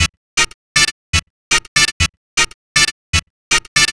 Session 08 - Disco Stab.wav